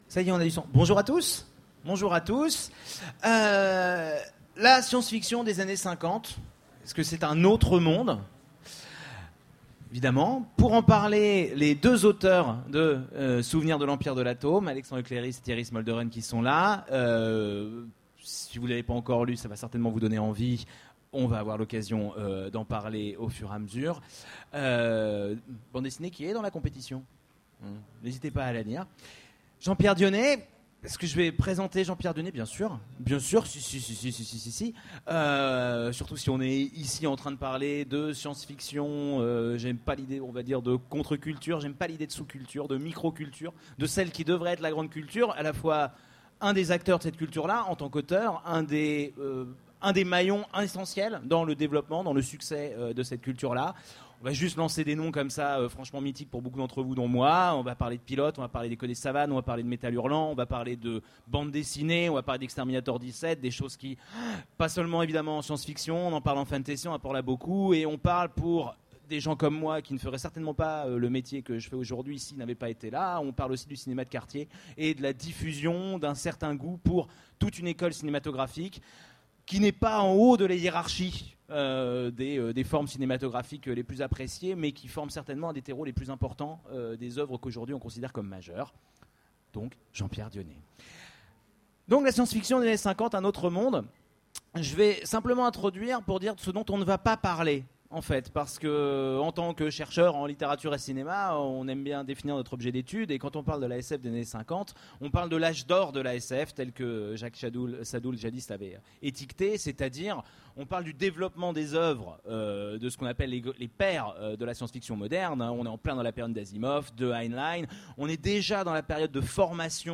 Utopiales 13 : Conférence La SF dans les années cinquante : un autre monde ?